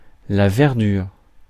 Ääntäminen
Synonyymit légume-feuille Ääntäminen France: IPA: [vɛʁ.dyʁ] Haettu sana löytyi näillä lähdekielillä: ranska Käännös Substantiivit 1. verdure 2. greenness Suku: f .